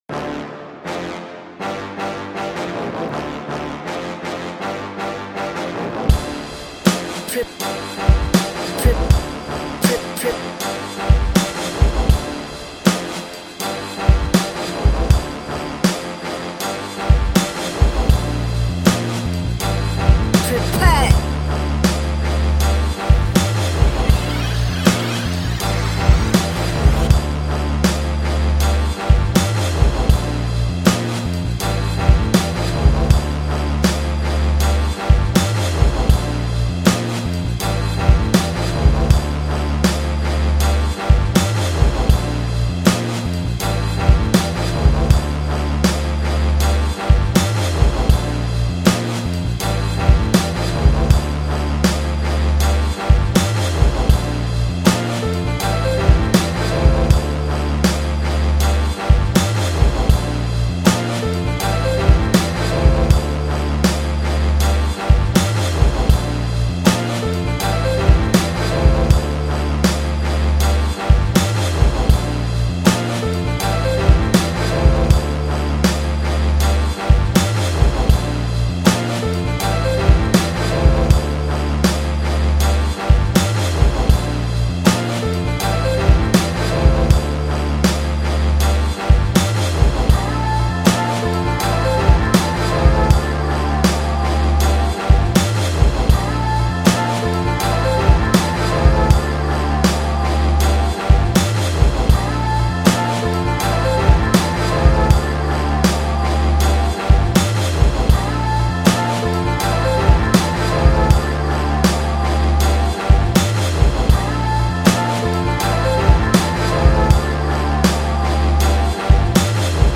instrumental Free beat
2020-02-10 1 Instrumentals, music productions 0